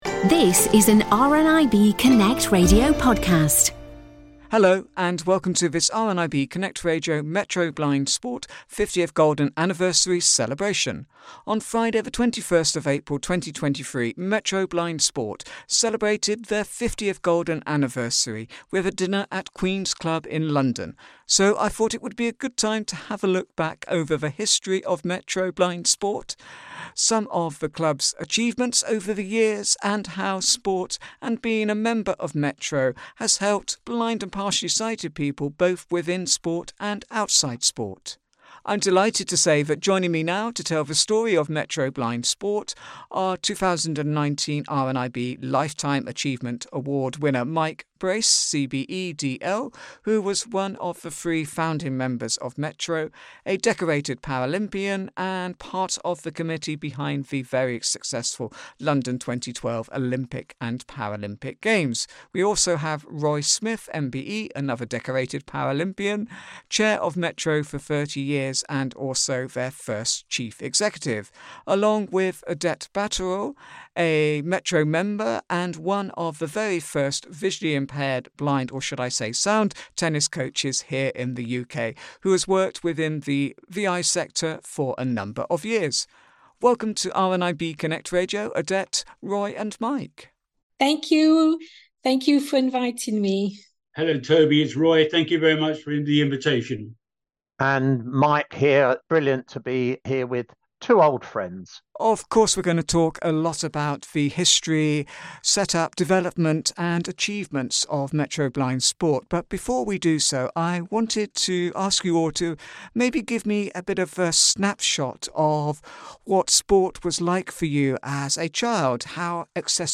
Metro Blind Sport 50th Golden Anniversary Panel Discussion